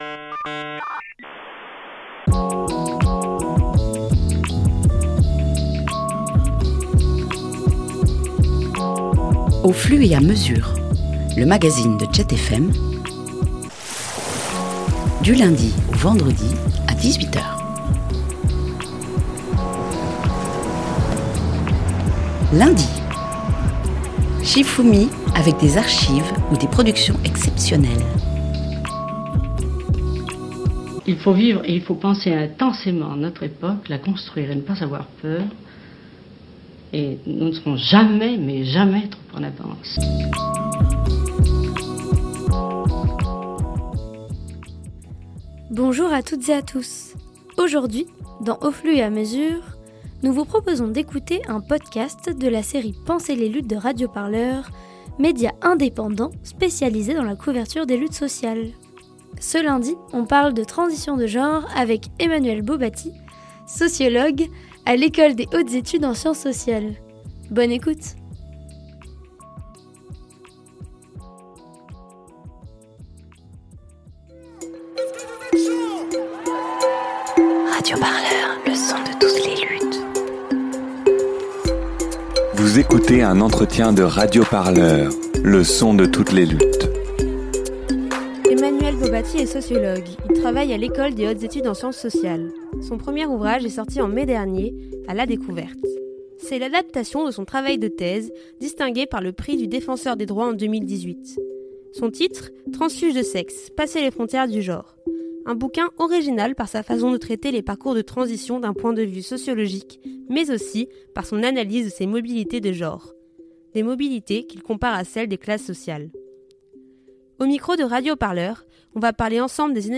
Dans cette série, on interroge des intellectuel-les, des militant-es chevronné-es, des artistes ou encore des chercheur-euses pour parler des luttes de France et d’ailleurs.